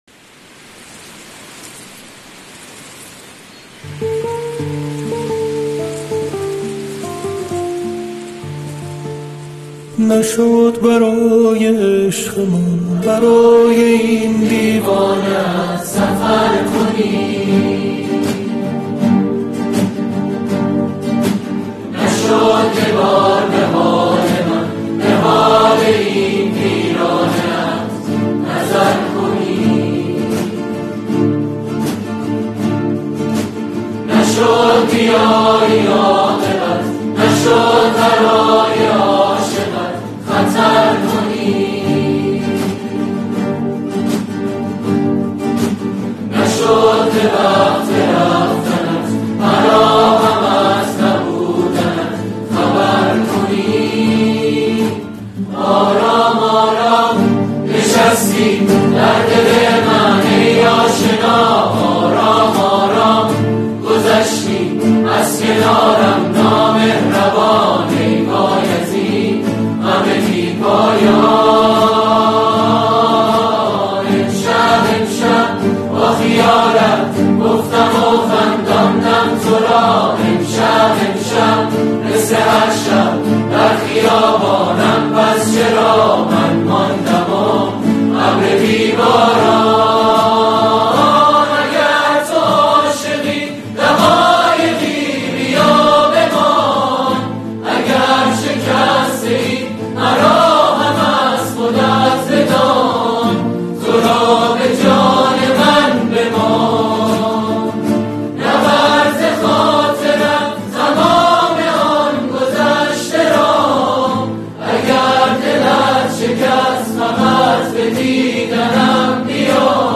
اجرای گروهی